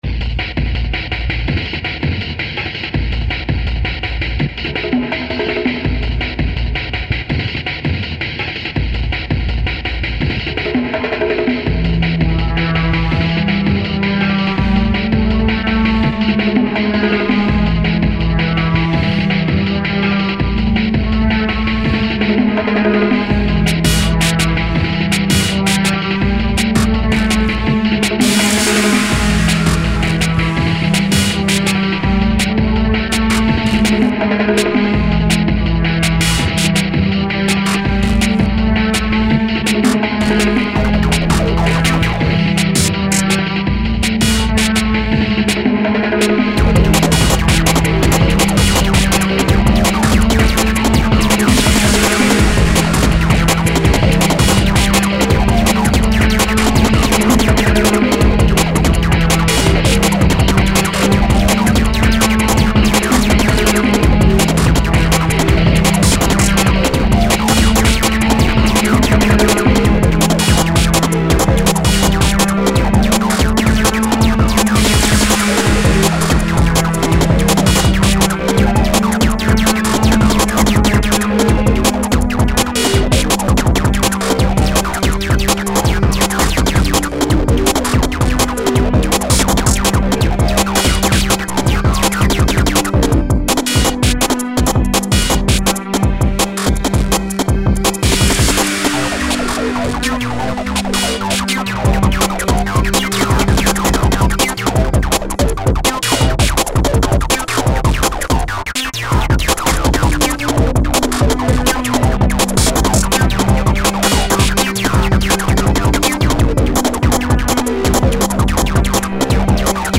File under: Electronica / IDM